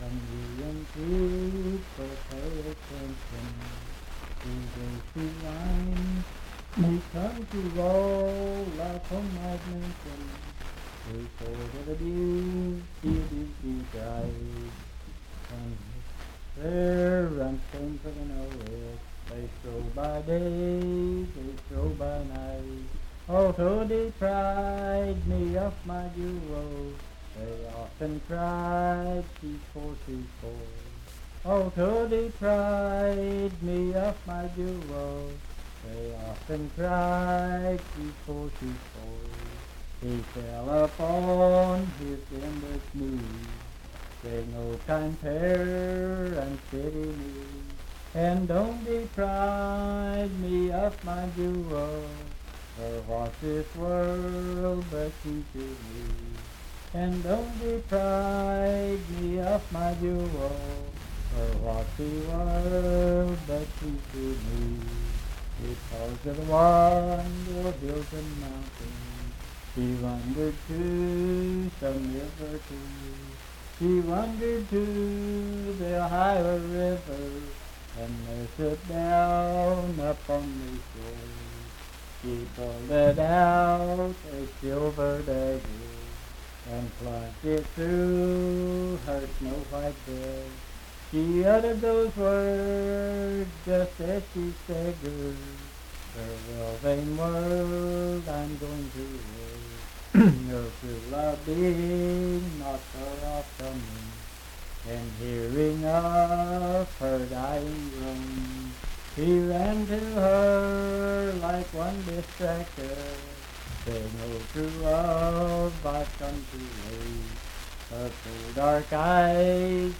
Unaccompanied vocal music
Voice (sung)
Pendleton County (W. Va.)